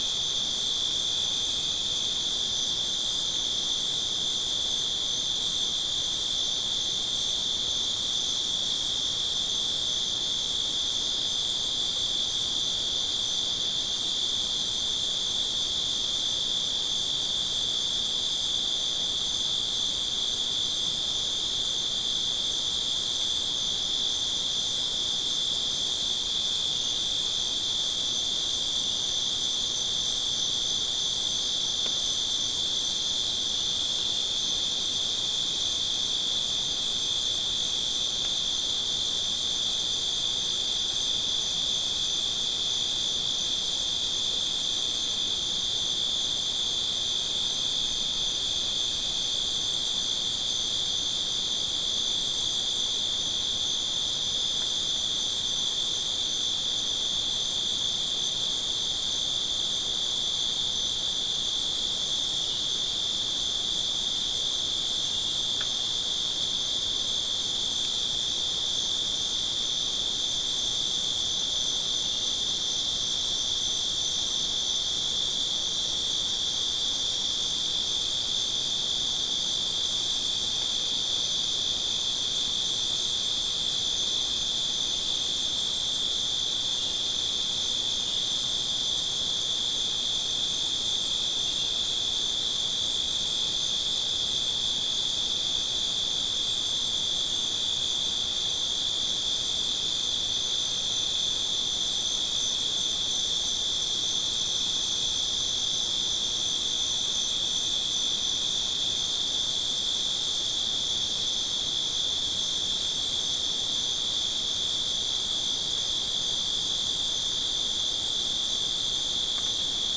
Non-specimen recording: Soundscape
Location: South America: Guyana: Sandstone: 1
Recorder: SM3